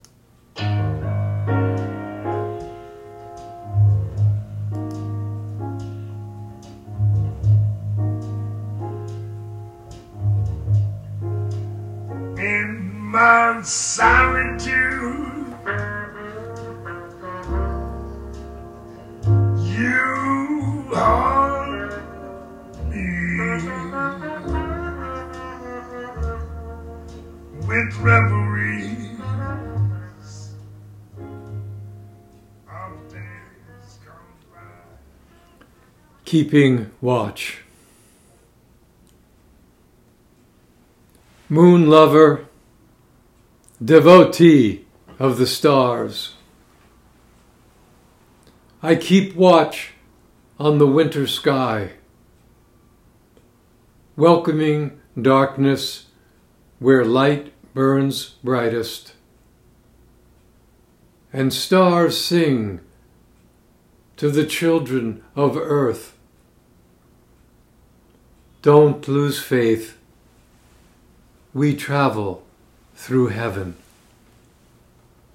Reading of “Keeping Watch” with music by Louis Armstrong.